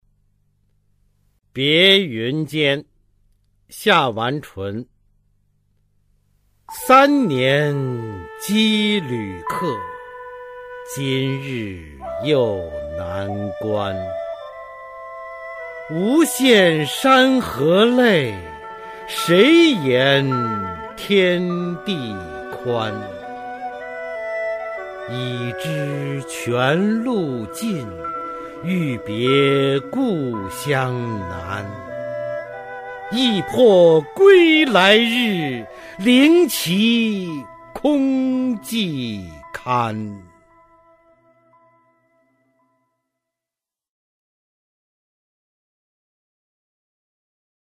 [明代诗词诵读]夏完淳-别云间 朗诵